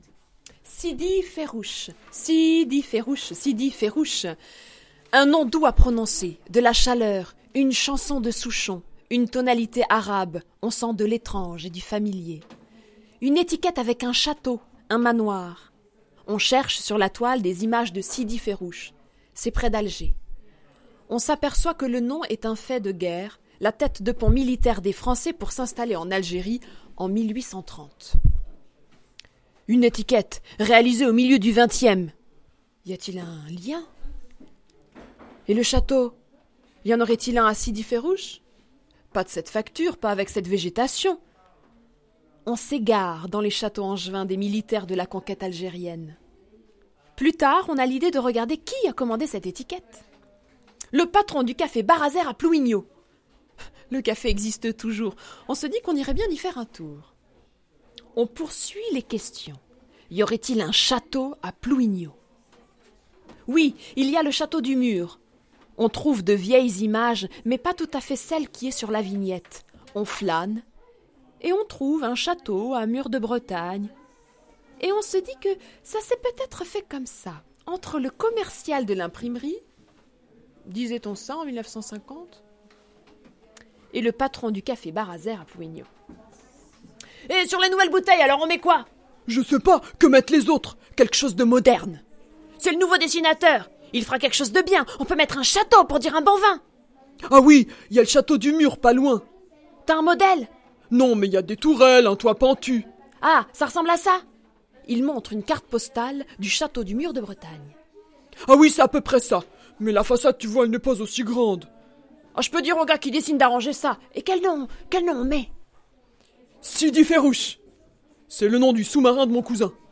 réalisé le 16 septembre 2017 lors de l’action Pam